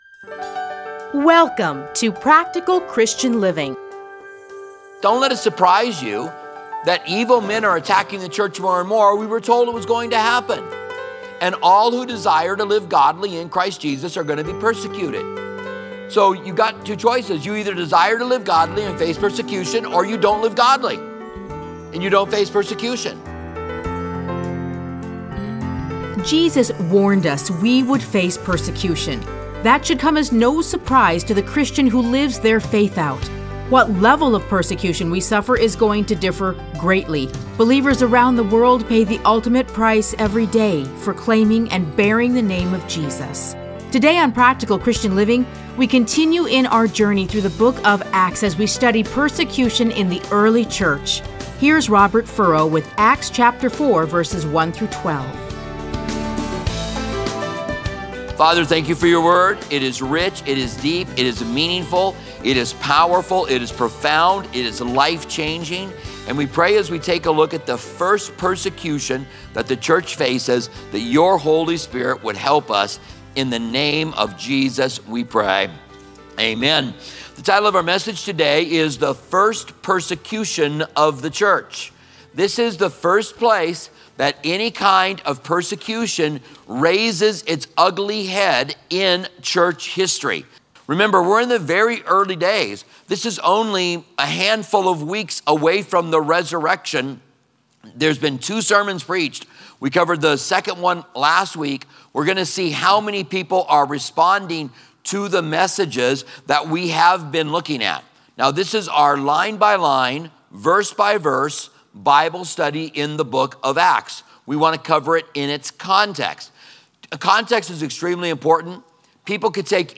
Listen to a teaching from Acts 4:1-12.